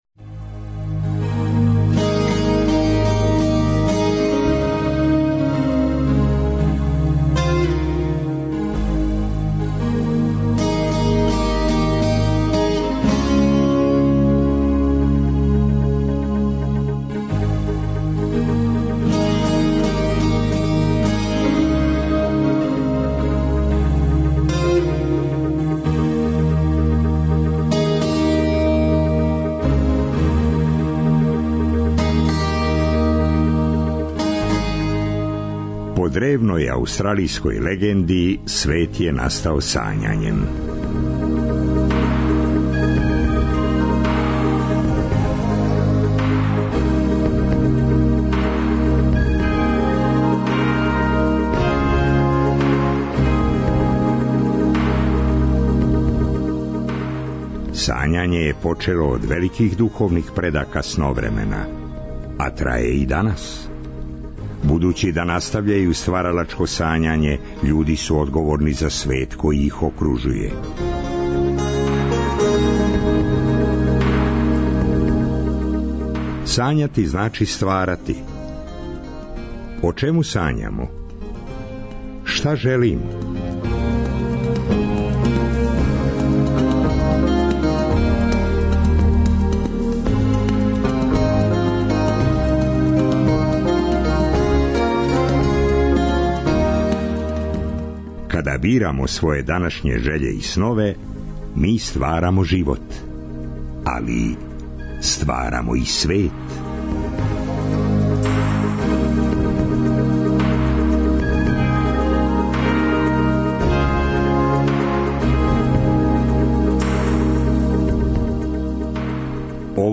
Путовања и музика...